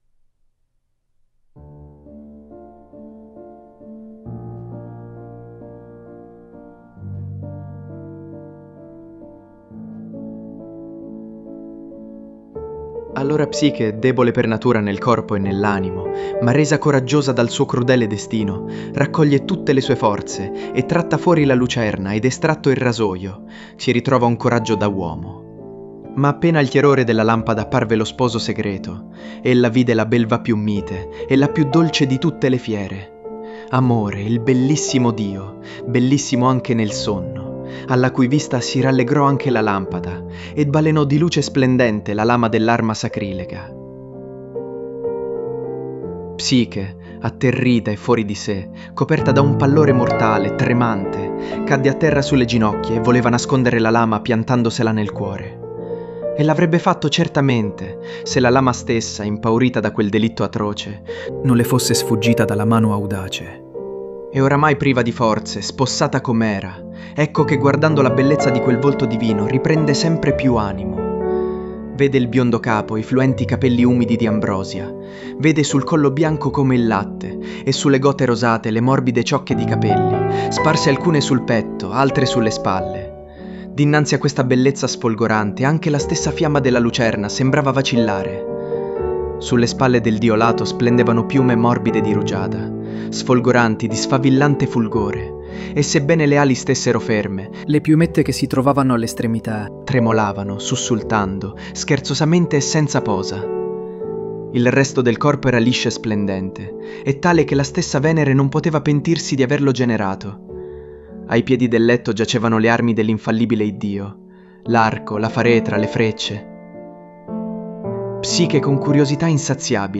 ha una dizione adorabilmente imprecisa, con una lieve cadenza meridionale, ma infonde una particolare passione nella sua recitazione.
La colonna sonora comprende la "Serenade" e il "Trio op. 100" di Schubert.
has a charmingly imprecise delivery, with a slight southern accent, but he brings a particular passion to his performance.
The soundtrack includes Schubert’s “Serenade” and “Trio, Op. 100”.